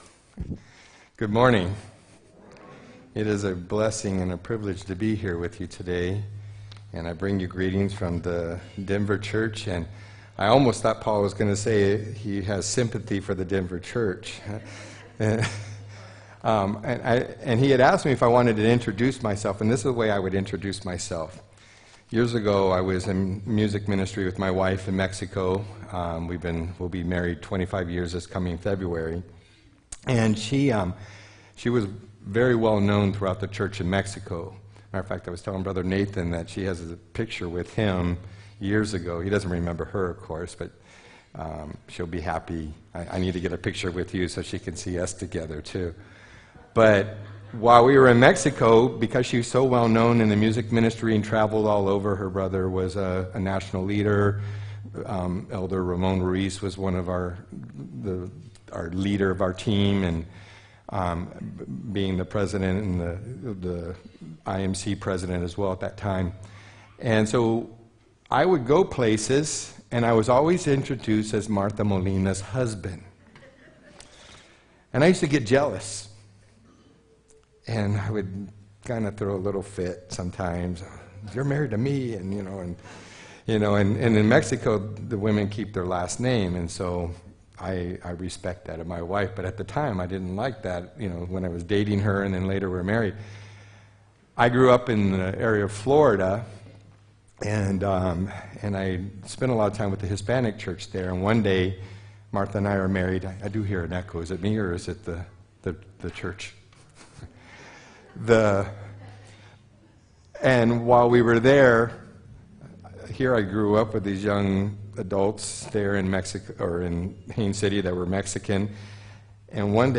10-13-18 sermon